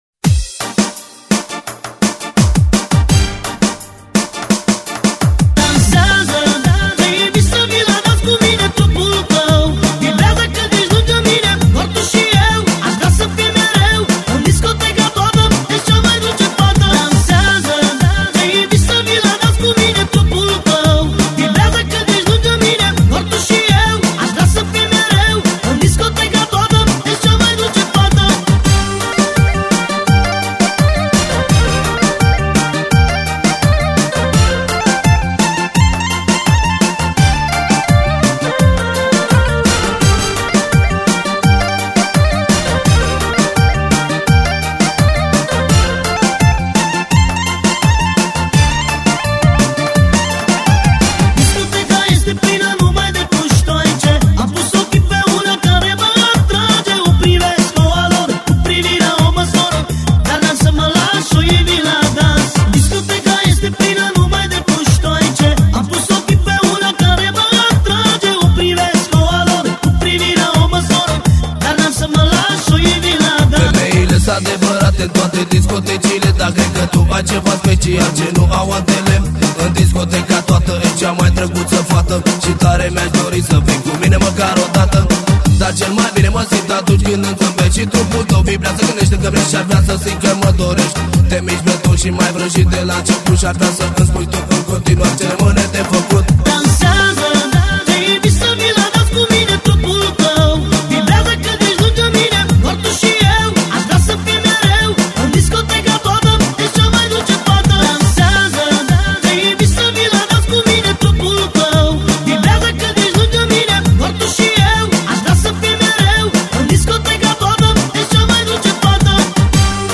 На арабов похоже.
Там в припеве слышится русский мат